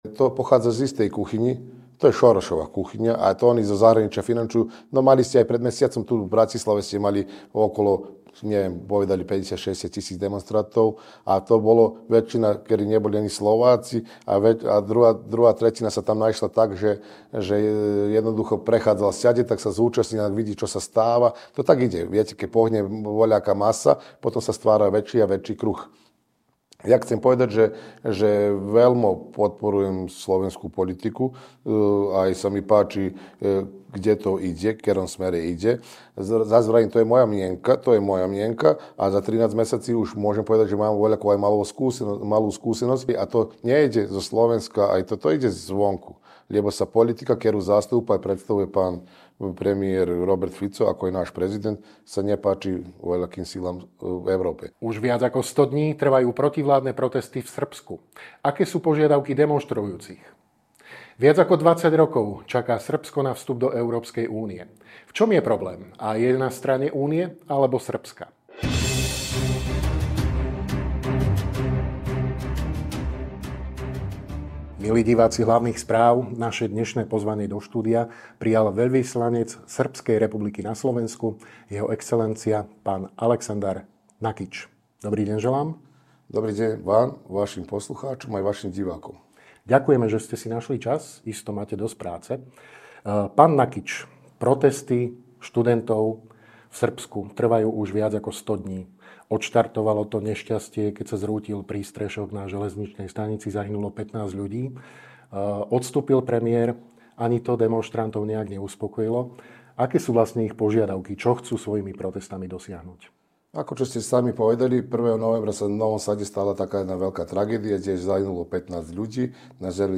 Srbský veľvyslanec Nakić pre HS bez servítky o tom, aká je skutočná pravda o protestoch